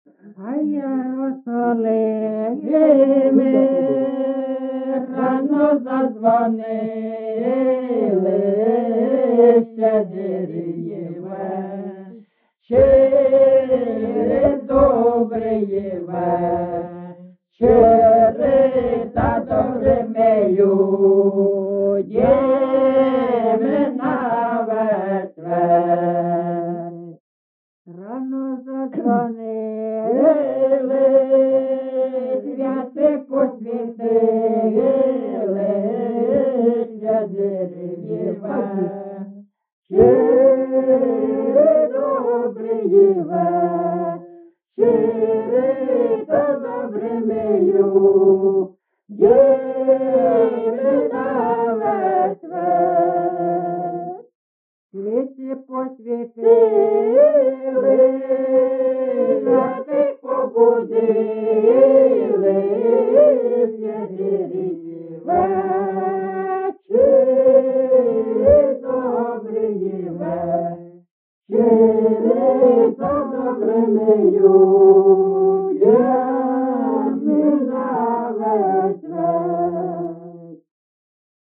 ЖанрЩедрівки
Місце записус. Лиман, Зміївський (Чугуївський) район, Харківська обл., Україна, Слобожанщина